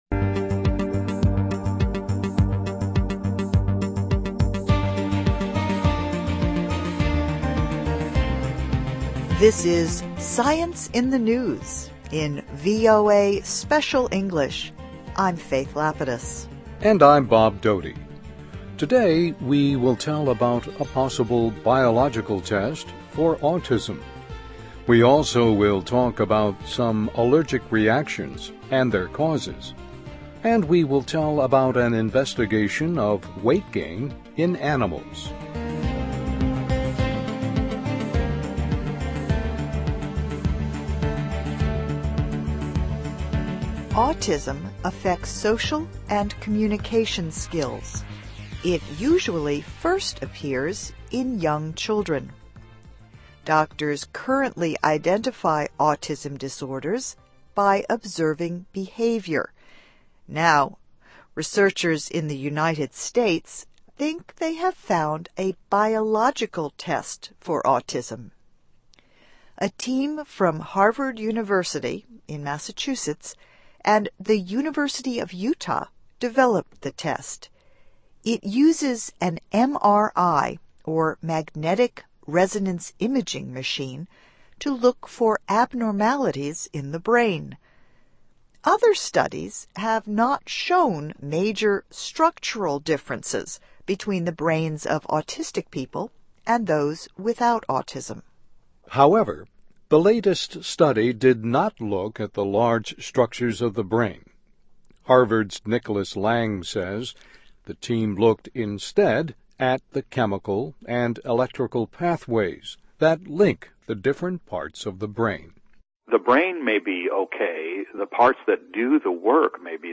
English Listening Practice.